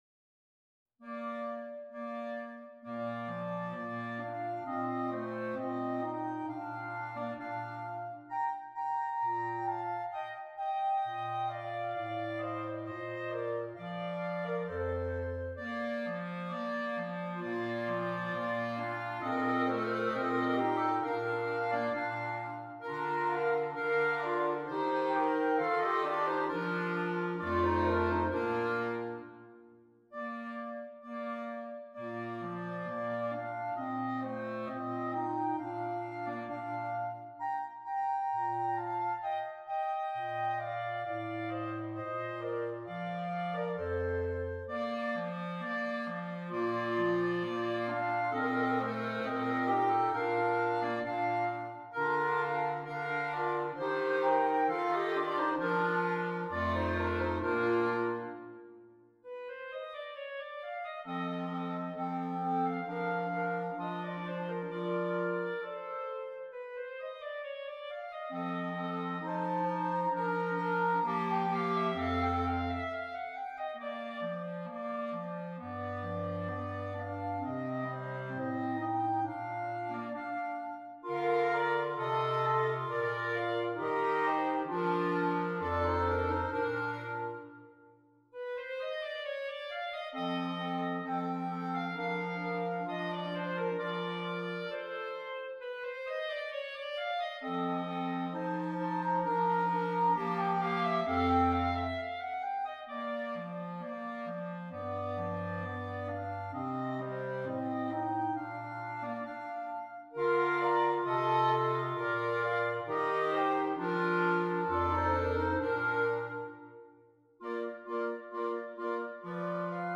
Voicing: Clarinet Quintet